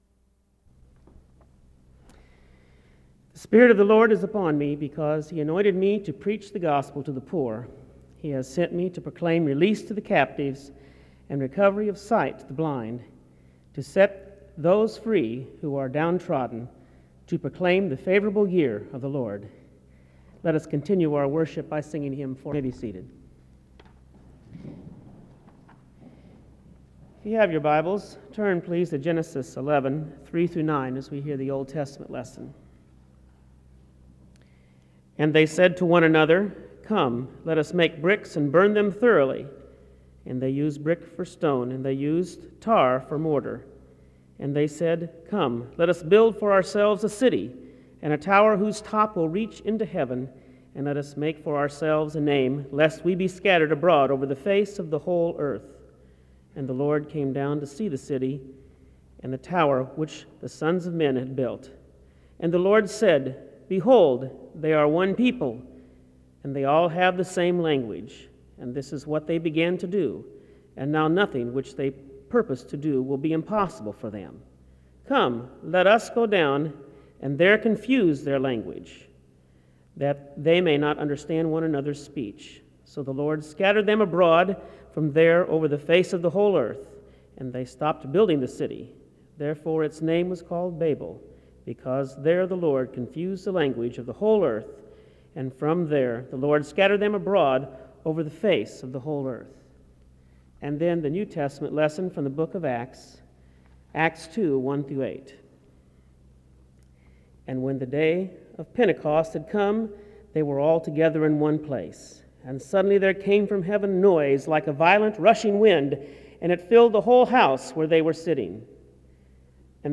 The service begins with a scripture reading from 0:00-0:21.
A prayer is offered from 2:49-3:55. 3:56-4:45.
She exhorts the chapel to be bridge builders through the work of the Holy Spirit and to be a bridge to those who are far from God. The service closes in prayer from 16:35-16:54.
Location Wake Forest (N.C.)